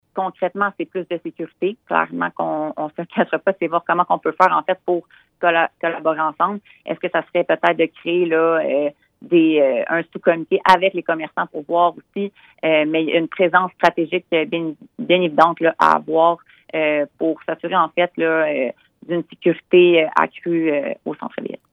Dans une entrevue à M105